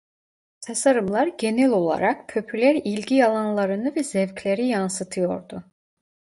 Pronounced as (IPA) /pɔ.py.lɛɾ̥/